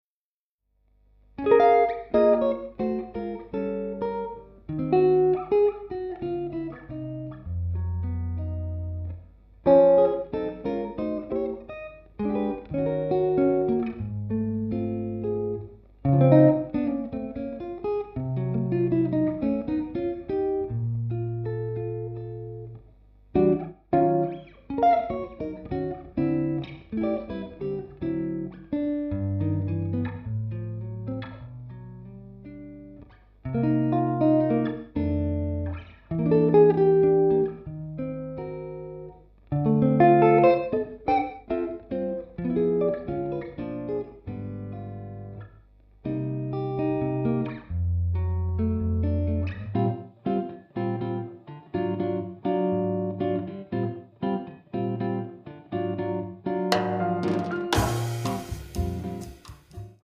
alto sax
piano
bass
drums
guitar
un samba aperto dolcemente dagli arpeggi
caratterizzato da una melodia molto romantica.